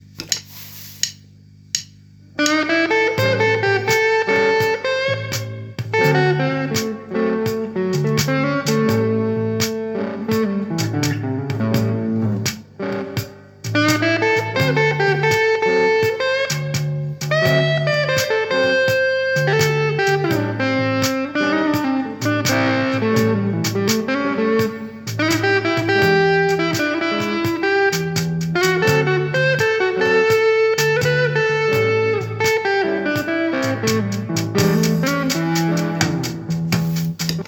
Hallo Leute, ich hatte vor drei Jahren einen Blues-Online-Kurs gekauft und möchte nun mit einer kurzen Bluesimprovisation mein "Können" präsentieren. Mit dem band creator trio + habe ich eine Bass- und Schlagzeugbegleitung kreiert und auf den looper die Rhythmusgitarre eingespielt. Mein Handy habe ich dann einfach vor den Gitarrenverstärker gestellt und mit der Diktiergerät-App alles aufgenommen.
Gibson SG special faded Peavy Rage 158 10 Watt Verstärker Marshall Bluesbreaker II Kong Reverb